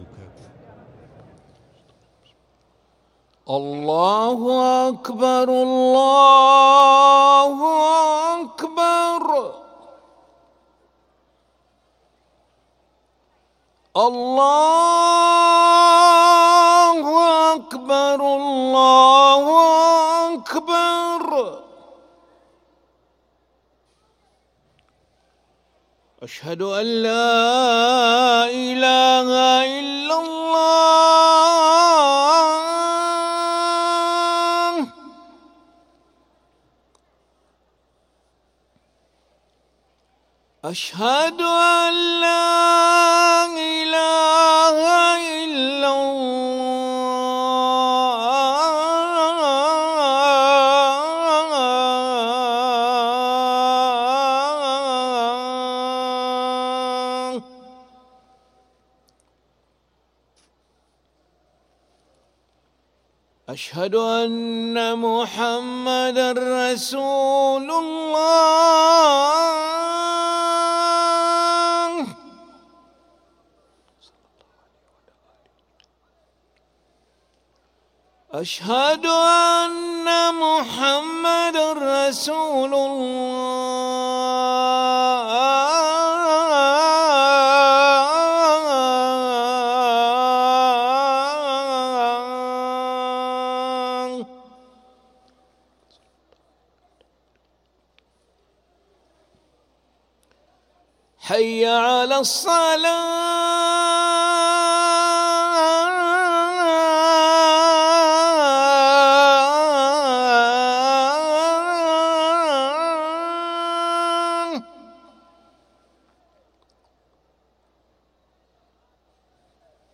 أذان العشاء للمؤذن علي أحمد ملا الخميس 26 ذو القعدة 1444هـ > ١٤٤٤ 🕋 > ركن الأذان 🕋 > المزيد - تلاوات الحرمين